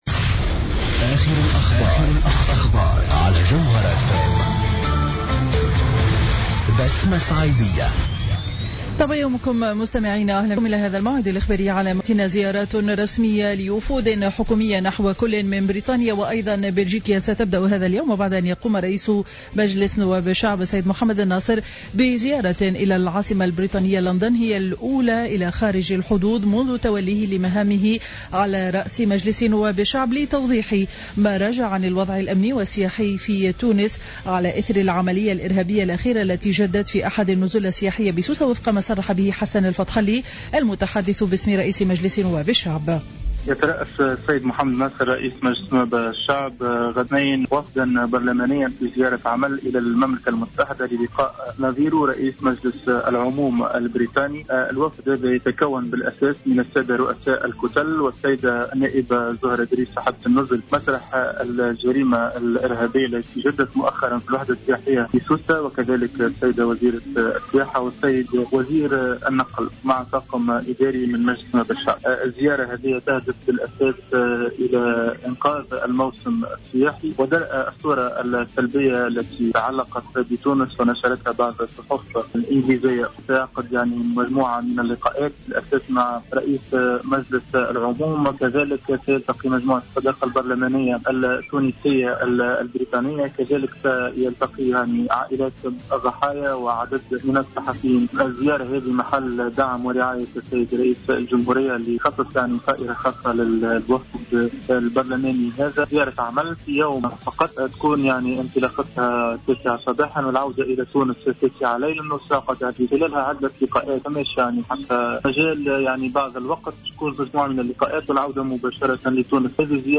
نشرة أخبار السابعة صباحا ليوم الإثنين 20 جويلية 2015